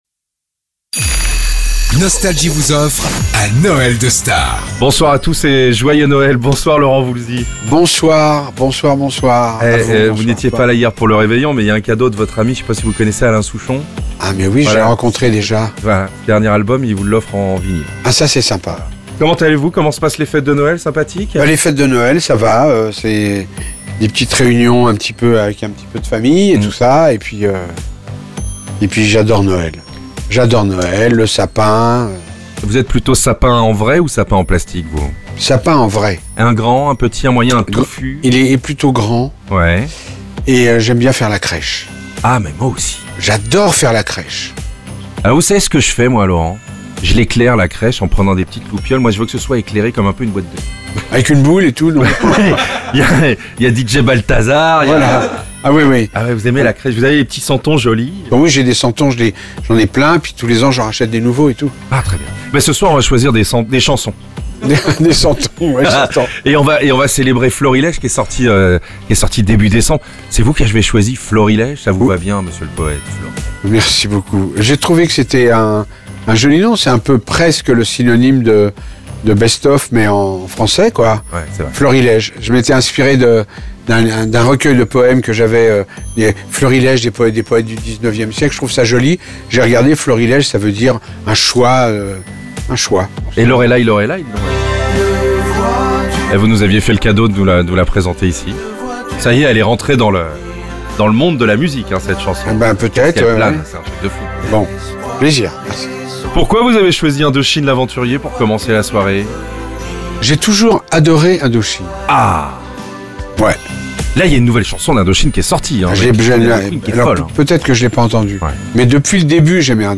Erweiterte Suche Noël de Stars avec Laurent Voulzy ! 15 Minuten 21.25 MB Podcast Podcaster Les interviews Les plus grands artistes sont en interview sur Nostalgie.